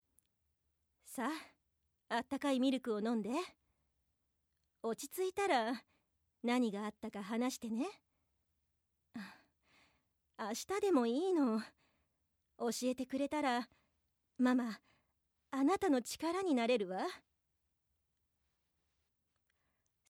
アニメ　２０代優しいママ